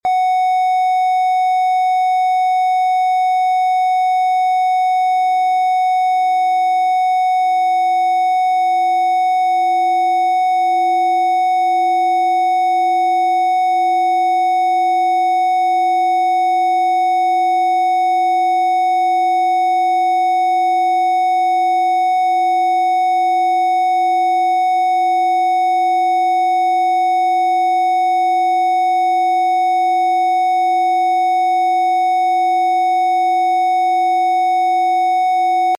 Cuenco tibetano + 963 Asi sound effects free download
Cuenco tibetano + 963 Asi despierta glandula pineal Activa tu ser superior con esta poderosa combinación: la frecuencia de 963 Hz de la conciencia divina y la resonancia sanadora de los cuencos tibetanos. Diseñado para estimular tu glándula pineal y llevarte a un estado de expansión de conciencia.